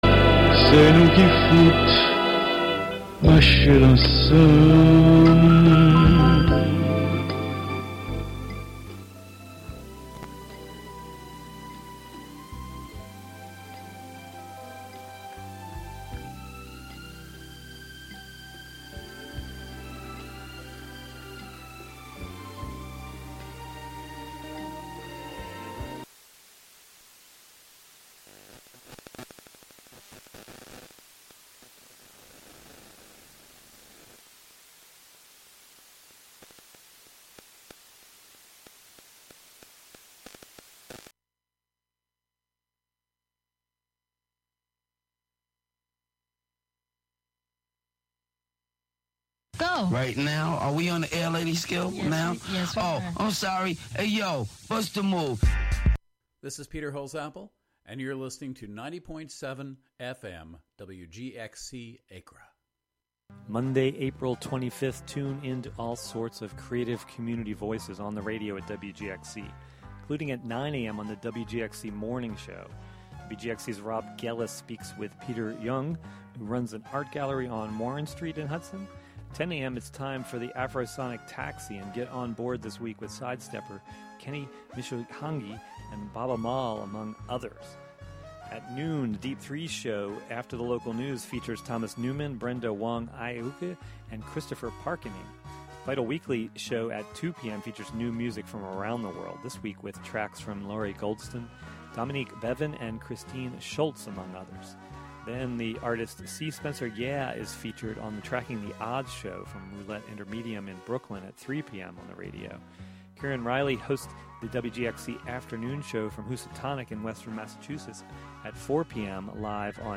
7pm Monthly program featuring music and interviews fro... Monthly program featuring music and interviews from Dutchess County resident broadcast live from WGXC's Hudson studio.